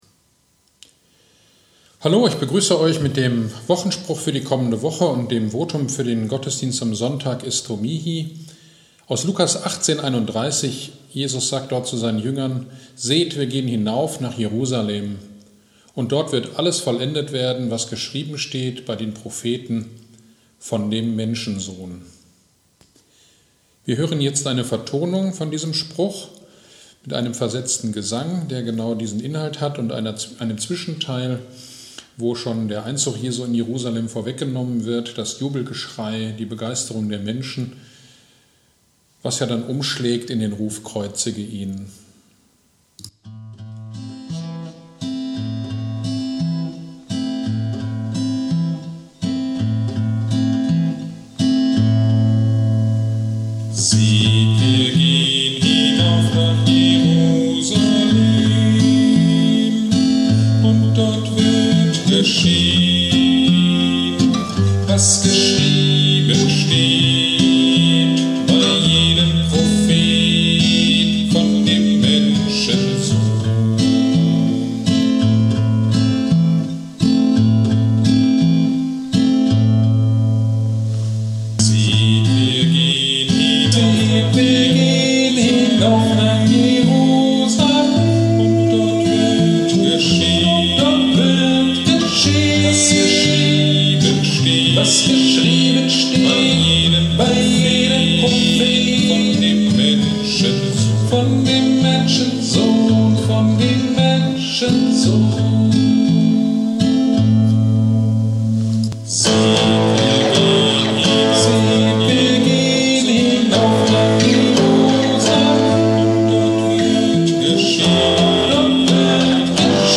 Gottesdienst am 14.02.21 Predigt zu 1. Korinther 13 - Kirchgemeinde Pölzig